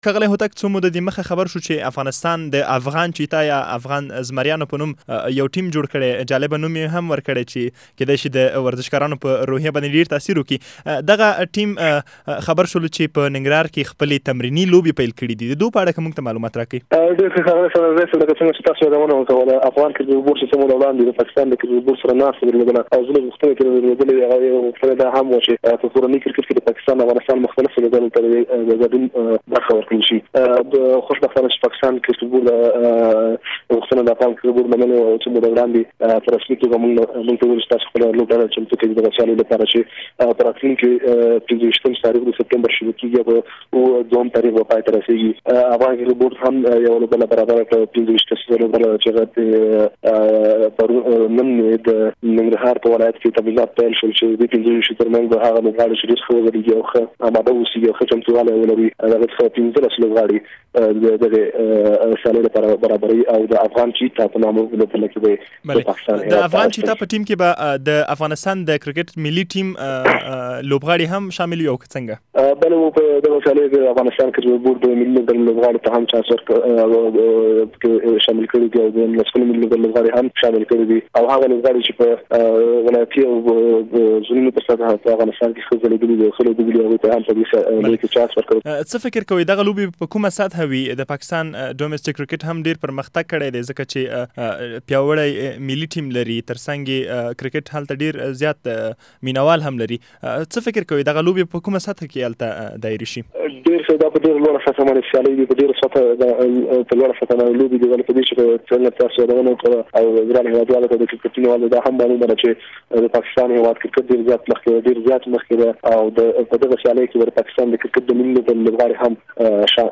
سپورټي مرکه